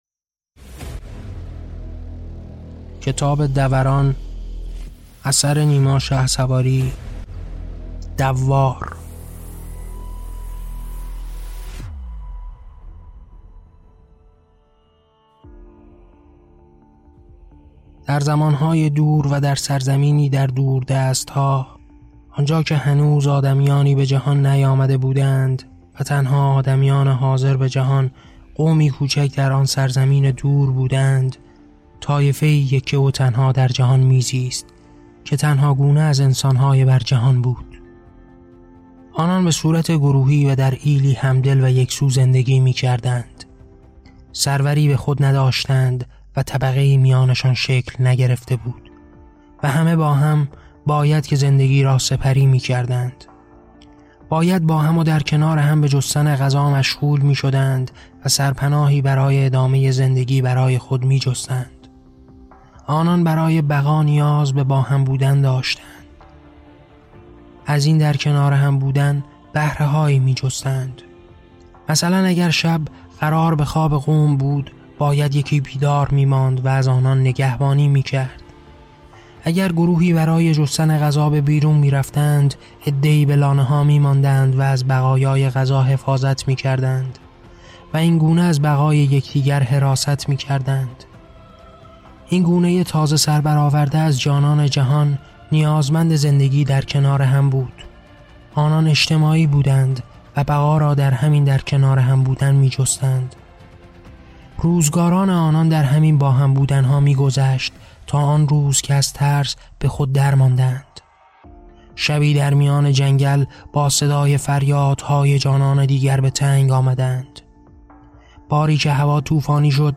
کتاب صوتی دَوَران - داستان کوتاه دَوار - اثر نیما شهسواری